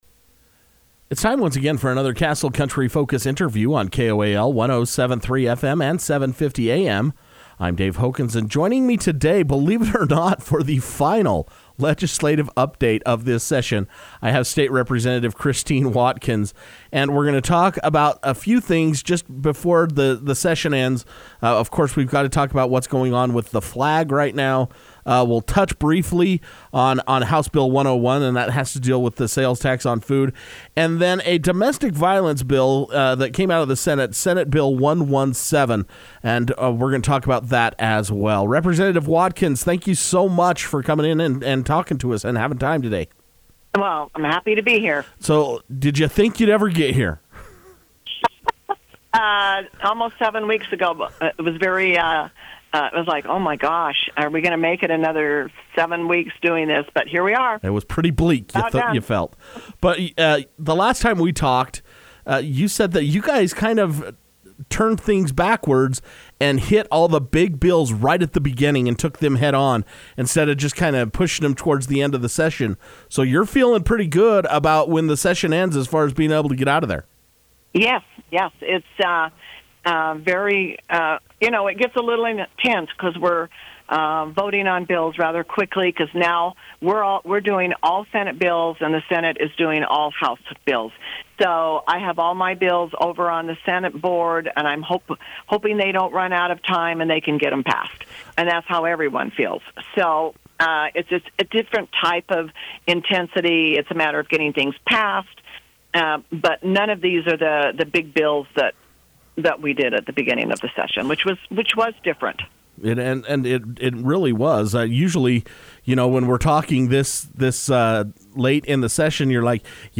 She will give a brief report on the dealings taking place on the hill twice a week, so she took time on Tuesday, Feb. 28 to speak over the telephone.